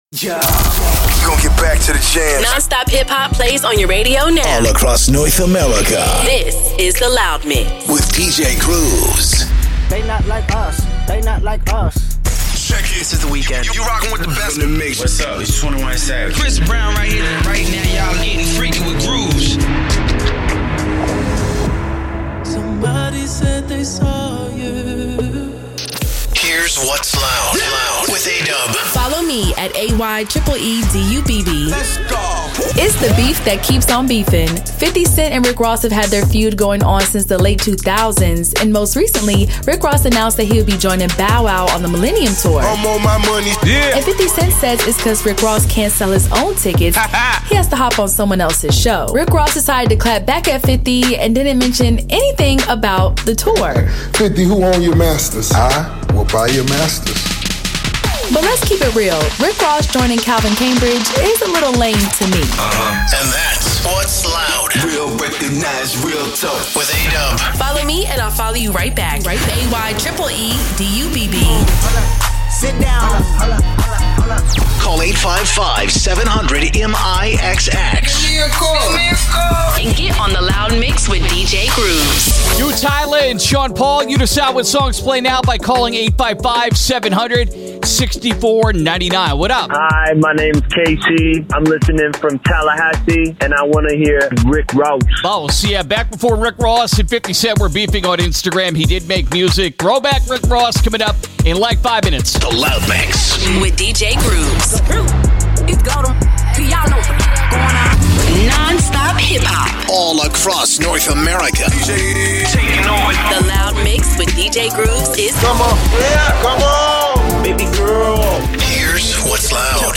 RHYTHMIC CHR & HIP-HOP FORMATS